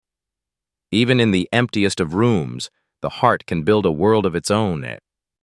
🎤 Emotional Quote TTS